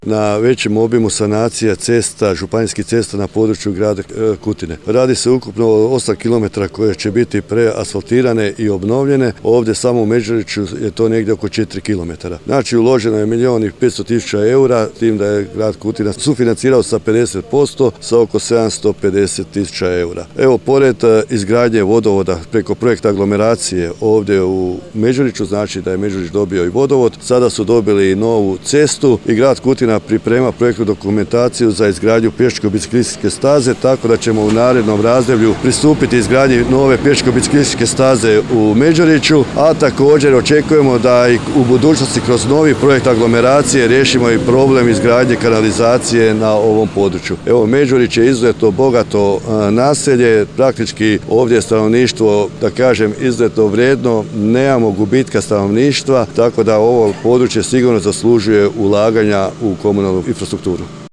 Gradonačelnik Kutine Zlatko Babić ovom prigodom zahvalio se Sisačko-moslavačkoj županiji i ŽUC SMŽ